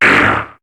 Cri de Medhyèna dans Pokémon HOME.